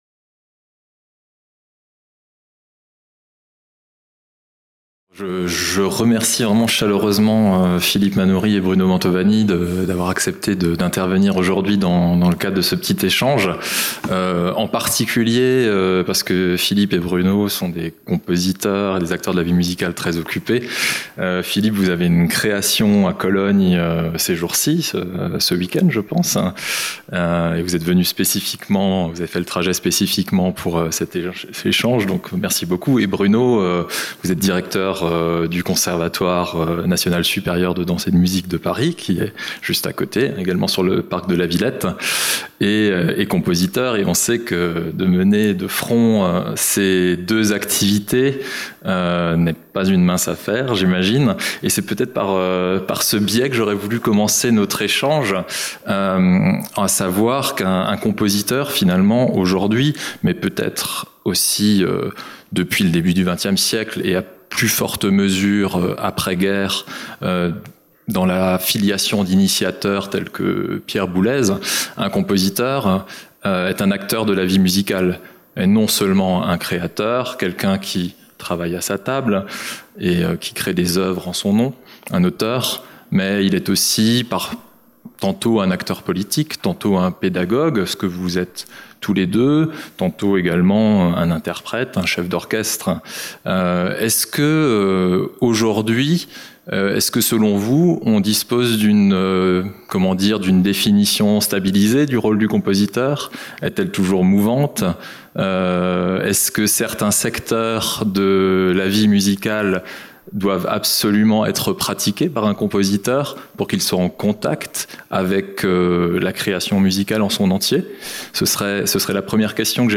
Philharmonie, salle de conférence 14h15 Processus de création : débat